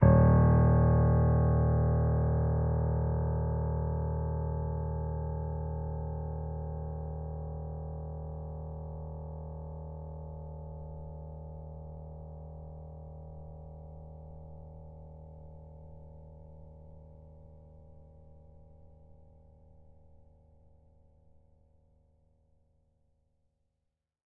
sampler example using salamander grand piano